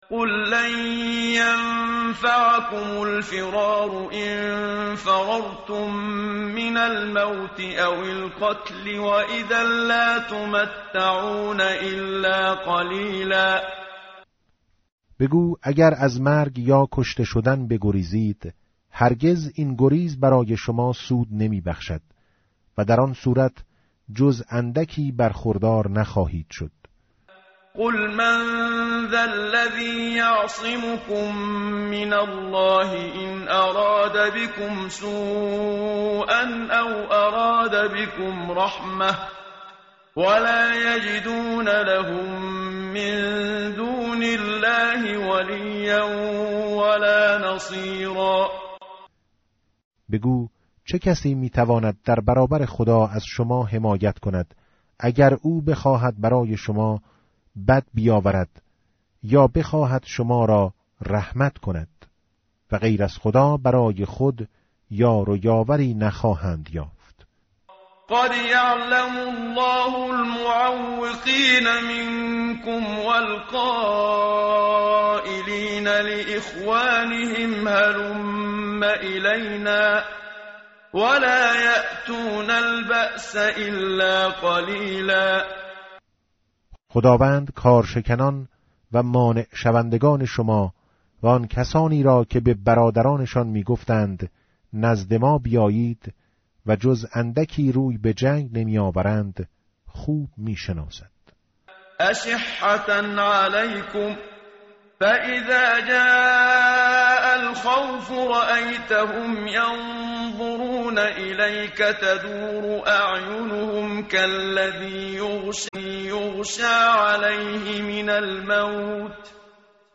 متن قرآن همراه باتلاوت قرآن و ترجمه
tartil_menshavi va tarjome_Page_420.mp3